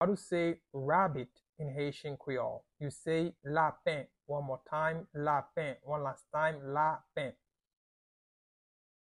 Pronunciation:
Rabbit-in-Haitian-Creole-Lapen-pronunciation-by-a-Haitian-teacher.mp3